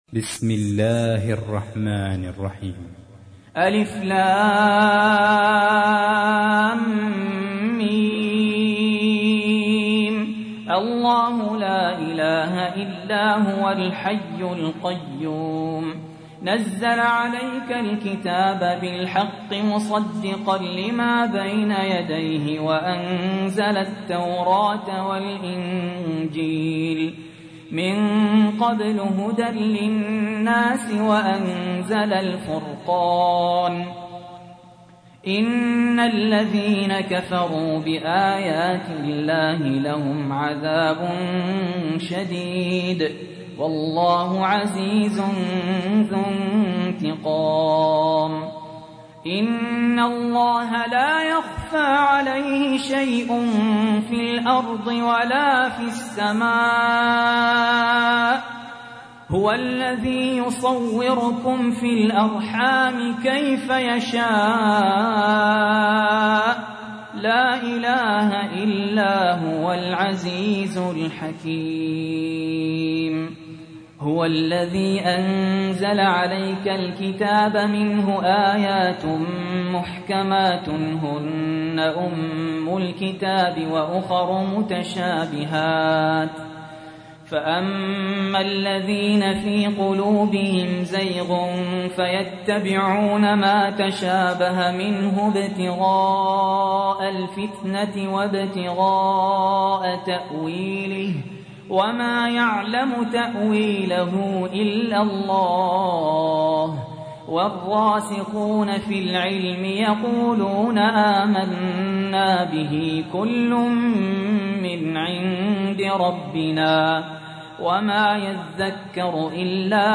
تحميل : 3. سورة آل عمران / القارئ سهل ياسين / القرآن الكريم / موقع يا حسين